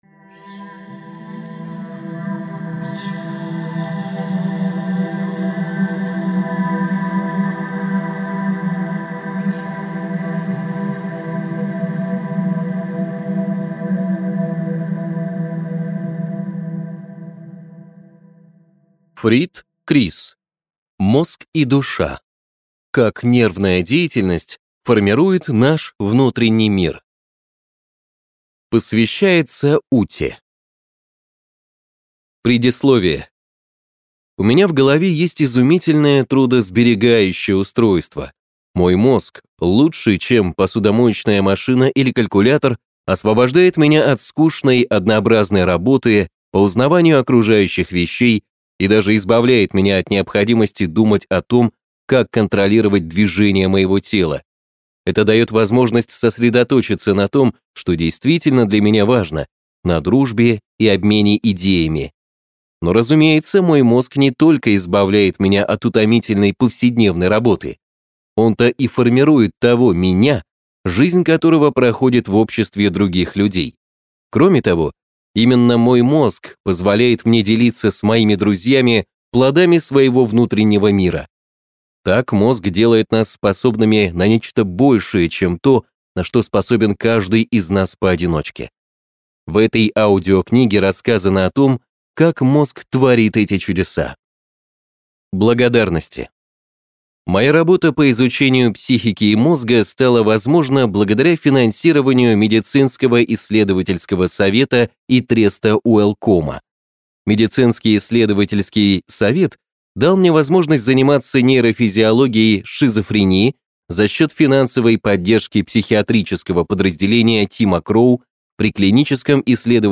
Аудиокнига Мозг и душа.